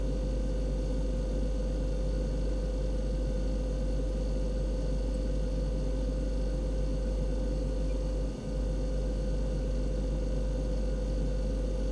fridge_hum.mp3